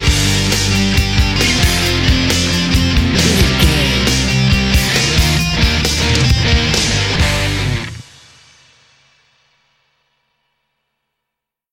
Ionian/Major
drums
electric guitar
bass guitar
pop rock
hard rock
lead guitar
aggressive
energetic
intense
nu metal
alternative metal